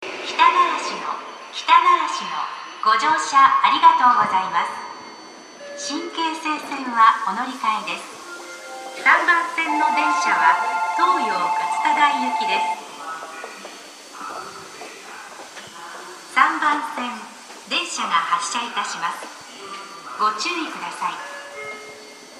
駅放送
2014年3月3日頃より接近メロディ付きの新放送に切り替わりました。
到着発車 響きやすく高音は割れる 接近放送は約10日間のみ使用された東葉快速の放送です。